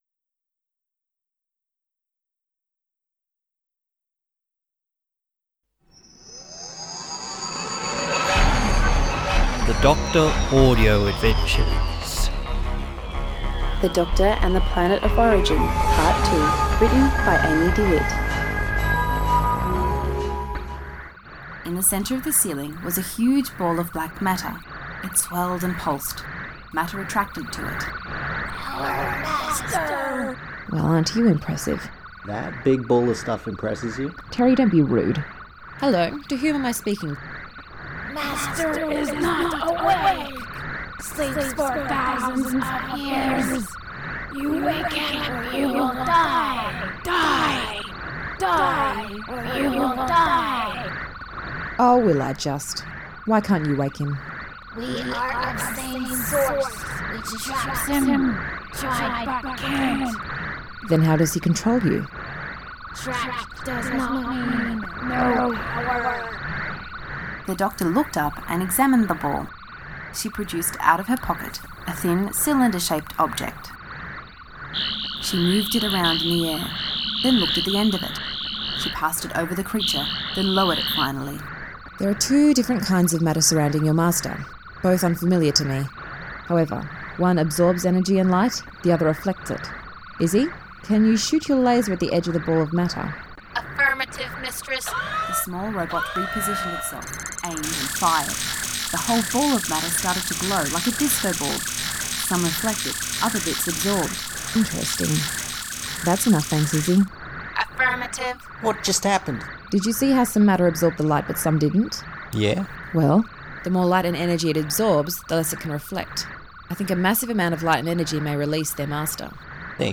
Music
SFX Creative Commons Attribution-NonCommercial 3 unless otherwise stated